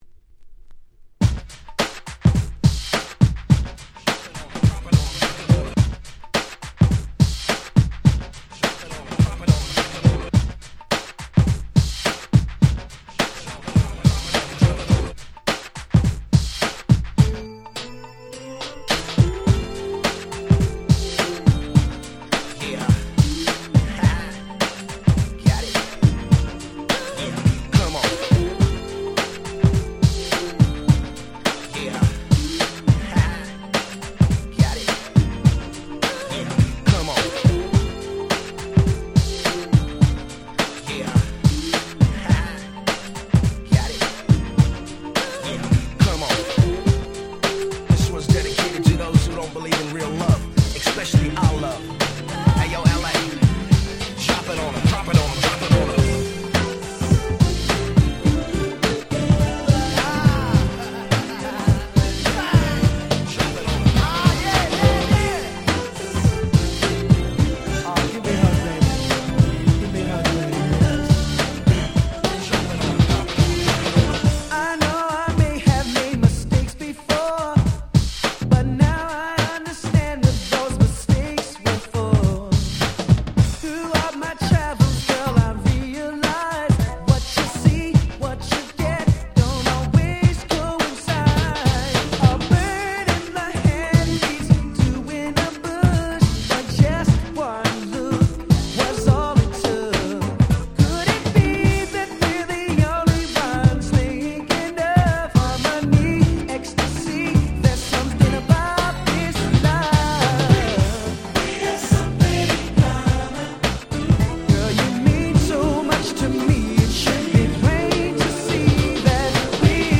93' New Jack Swing / R&B Classics !!